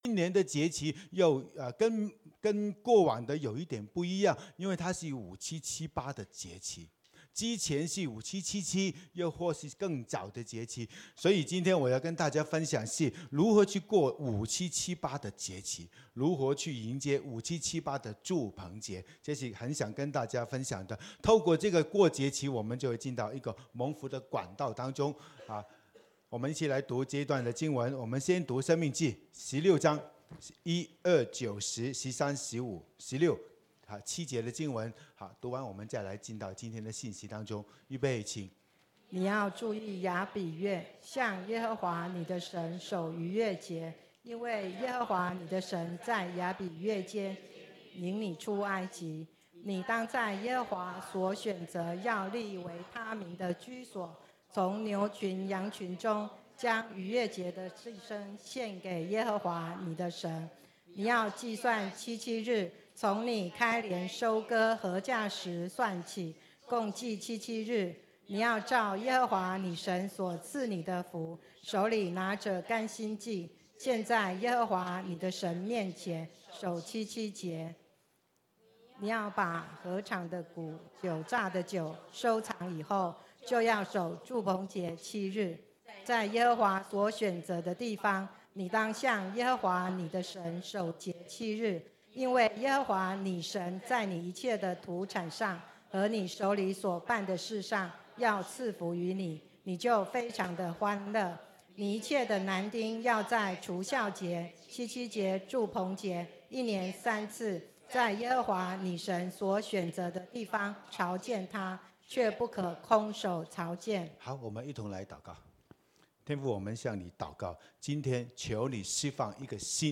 b_主日信息